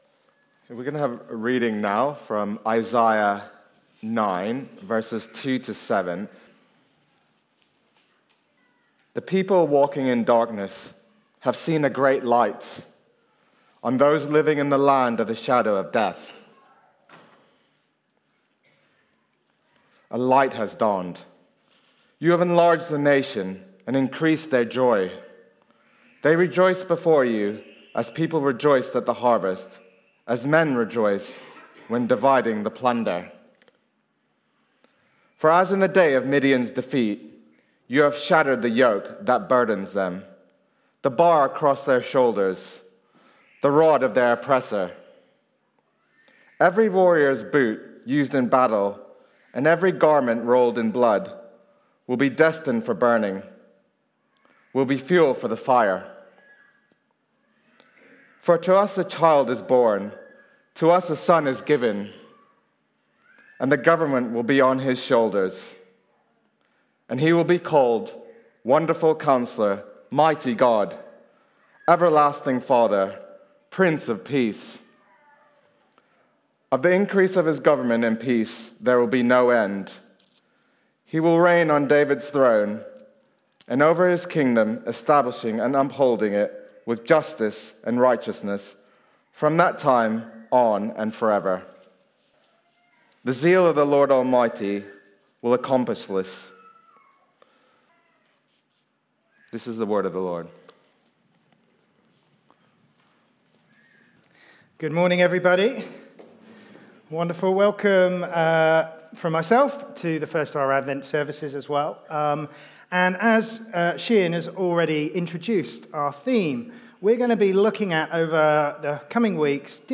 Media for Sunday Service on Sun 01st Dec 2024 10:00
Theme: The Gift of Hope Sermon